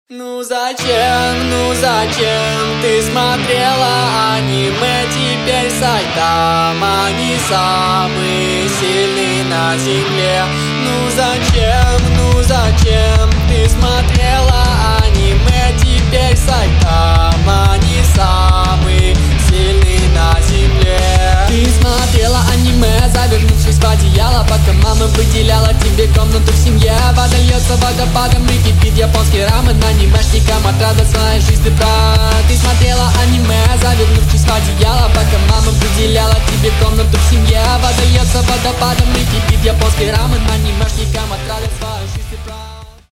Скачать припев: